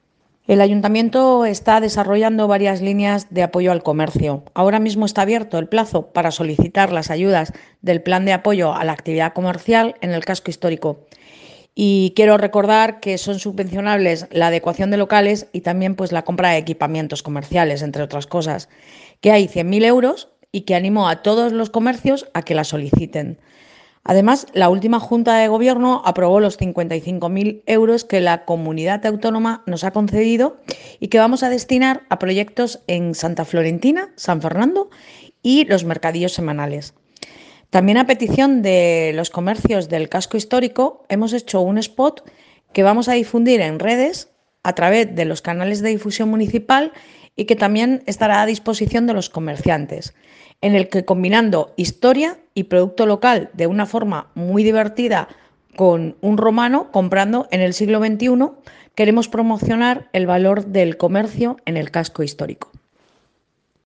Enlace a Declaraciones de Belén Romero